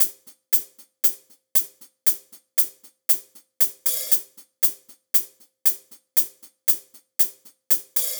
11 Hihat.wav